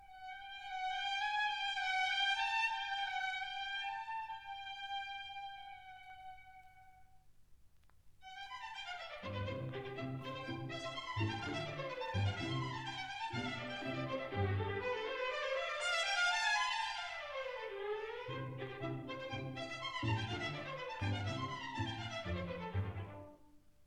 conductor
No. 1 Studio, Abbey Road, London